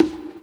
Bongo_.wav